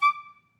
Clarinet
DCClar_stac_D5_v2_rr2_sum.wav